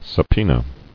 [sub·poe·na]